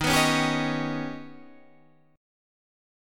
D#+7 chord